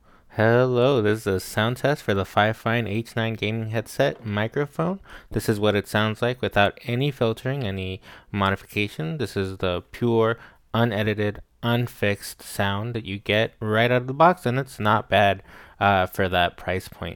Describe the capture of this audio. The sound quality of this mic out of the box is phenomenal. You aren’t getting a professional-sounding mic, but you are getting a very clear and intelligible sound which is all you really need when it comes to headsets. Mic Sound Check Out of the Box